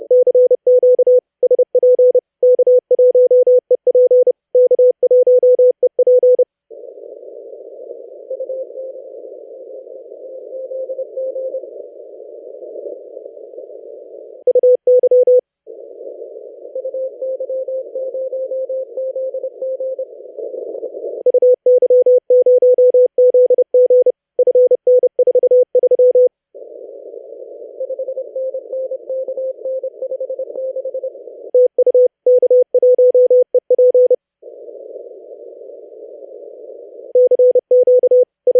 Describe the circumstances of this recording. inverted L about 30M at the top with 4 elevated radials. recording of the QSO from my side.